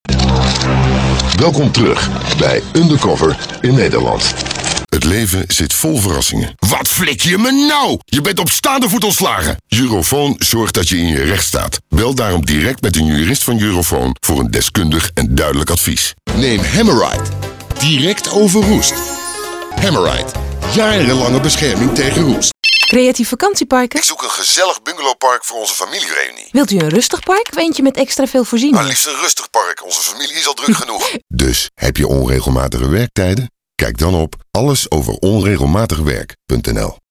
Deep, warm & trustworthy experienced voice over with impact.
Sprechprobe: Werbung (Muttersprache):